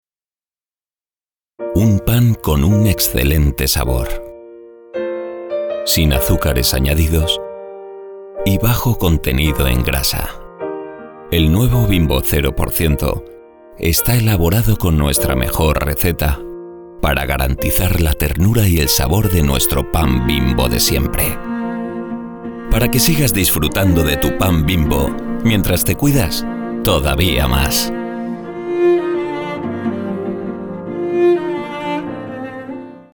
• 2Spanish Male No.2
Charming